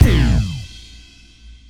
Kick15.wav